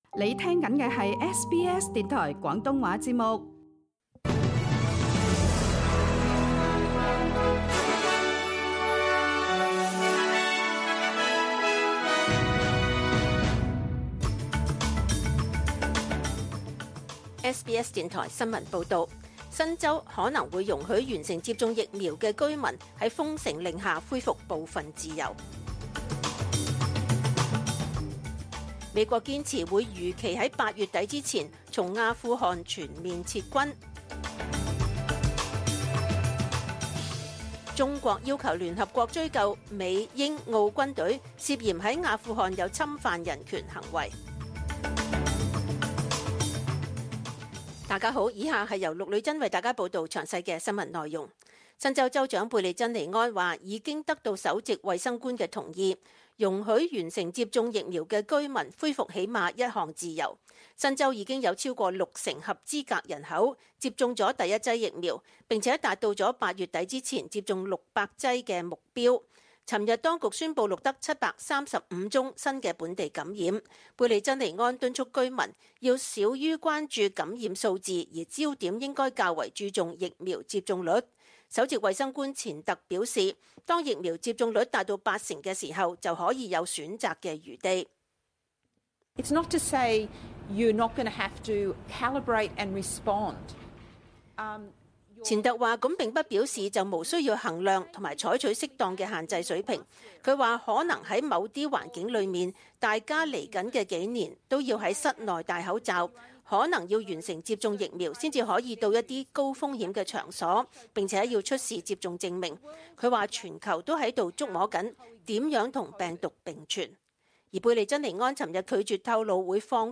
SBS 中文新闻（八月二十五日）